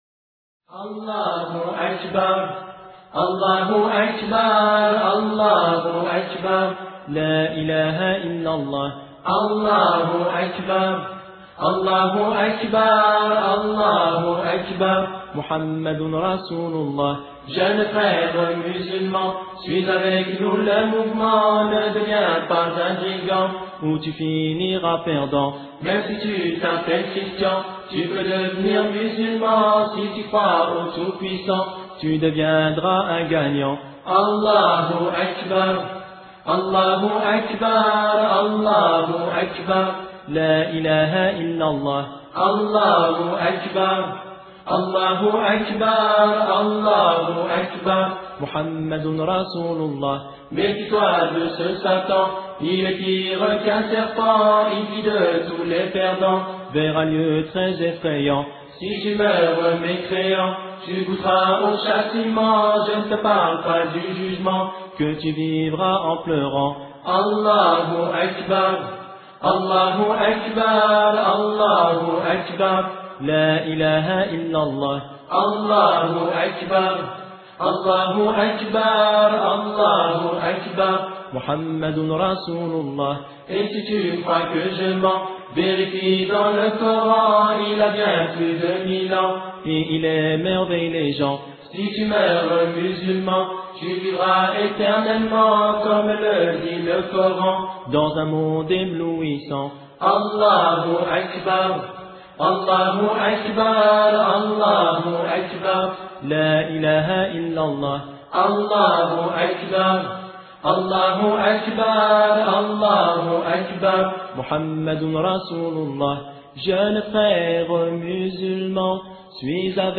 louange a Dieu orateur: quelques jeunes période de temps: 00:00:00